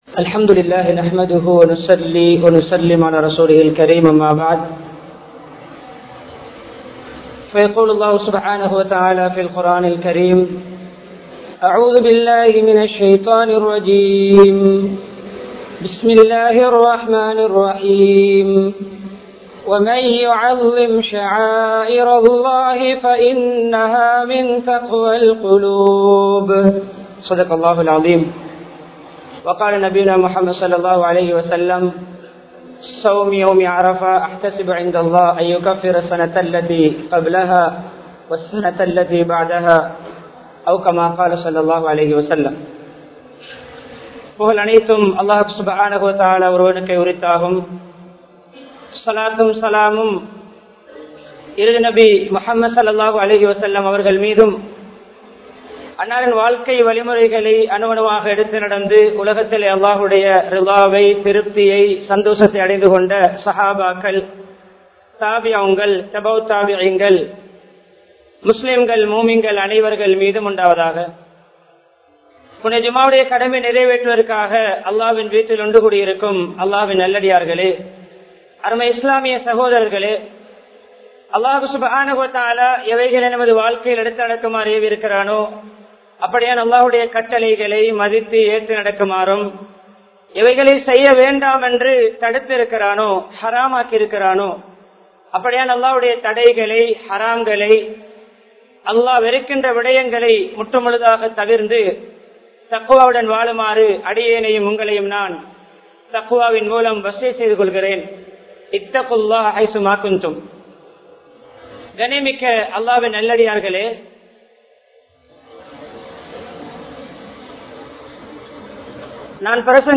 Ibrahim(Alai)Avarhalin MunMatthirihal (இப்றாஹிம்(அலை) அவர்களின் முன்மாதிரிகள்) | Audio Bayans | All Ceylon Muslim Youth Community | Addalaichenai
Akbar Town Jumua Masjidh